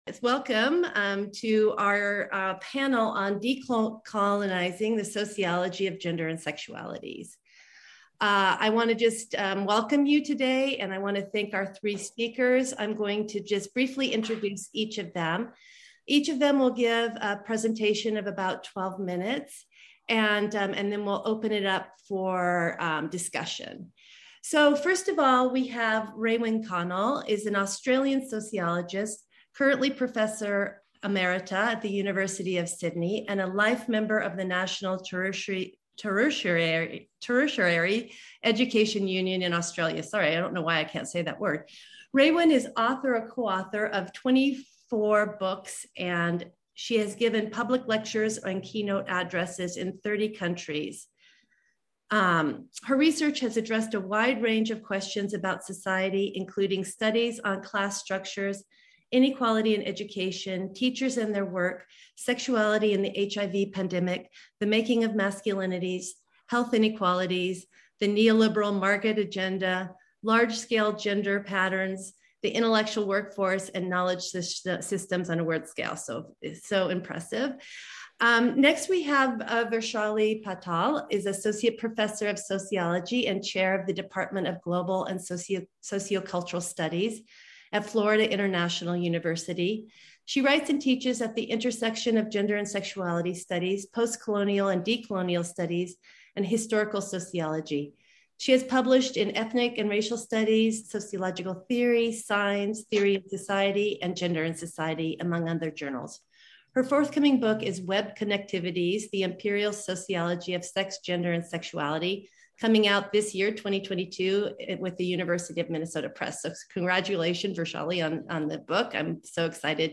This is an audio recording of the online panel event, which took place on: April 10, 2022 at 5pm-6pm EST (April 11, 2022 8am-9am AEDT)